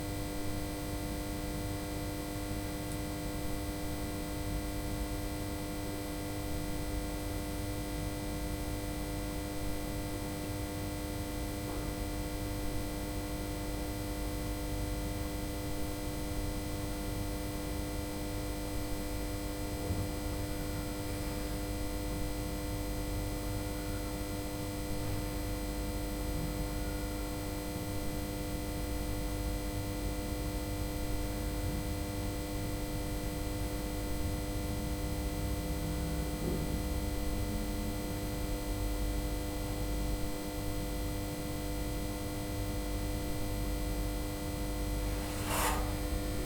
cfl-buzz-modulating.mp3